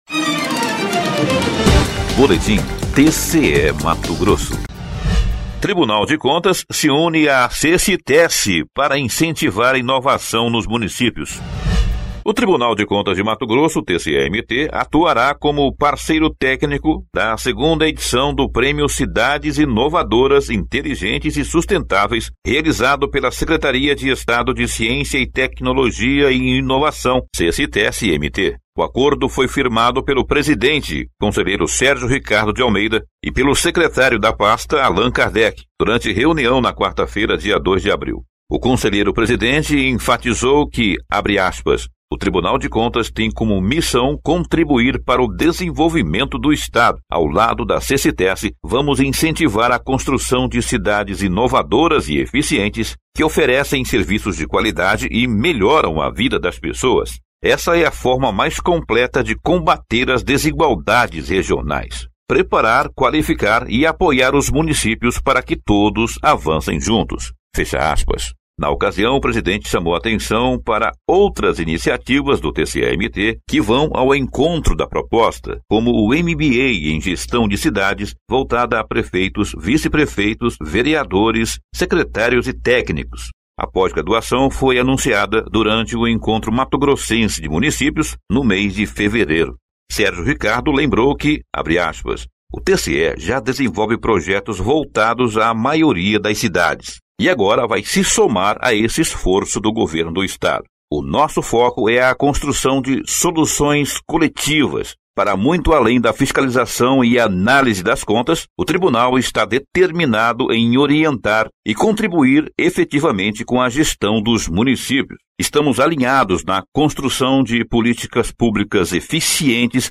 Sonora: Allan Kardec – secretário da Seciteci-MT